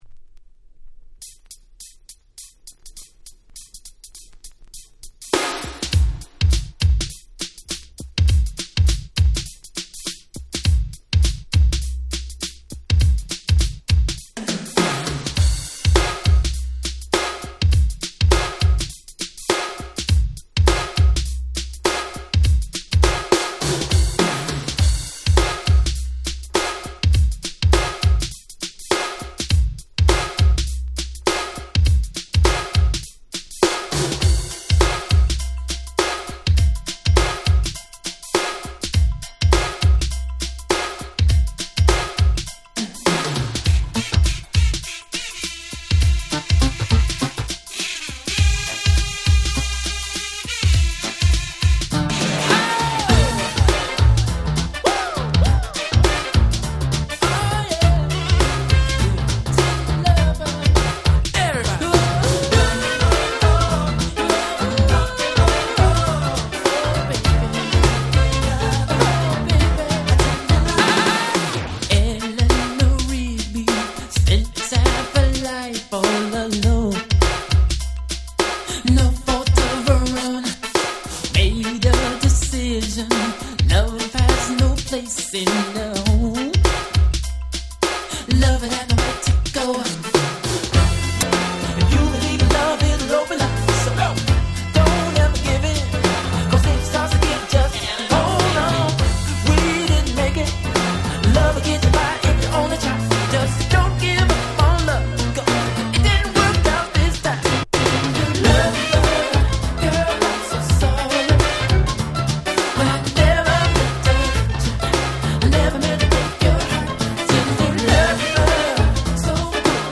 89' Super Hit R&B / New Jack Swing !!
最高のニュージャックスウィングナンバー！！